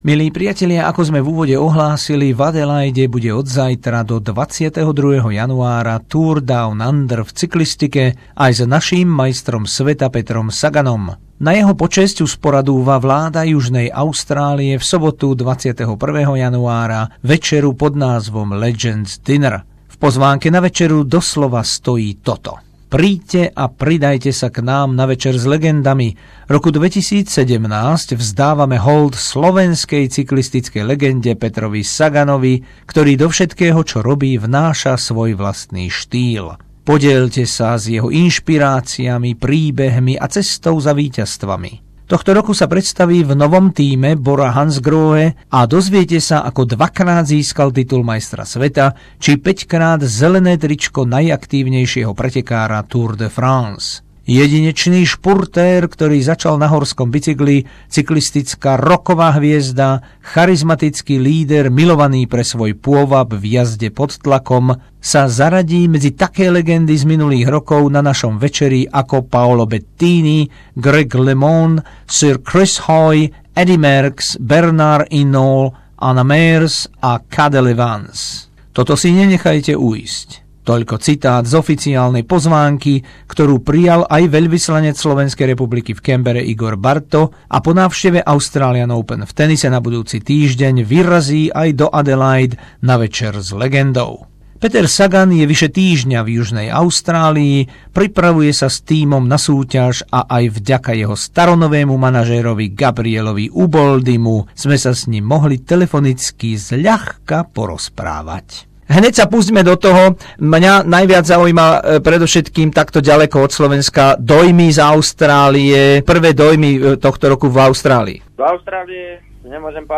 Rozhovor s Petrom Saganom
Rozhovor so slovenskou cyklistickou legendou Petrom Saganom, dvojnásobným majstrom sveta a päťnásobným držiteľom zeleného trička Tour de France, počas jeho pobytu v Adelaide